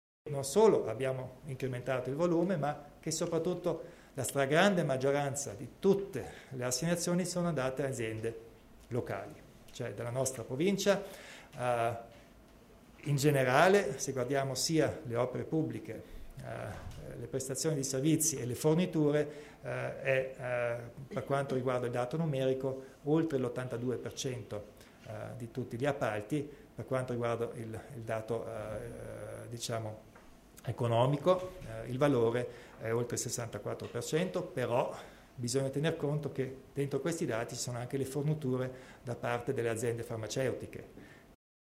Il Presidente Kompatscher illustra le novità in tema di appalti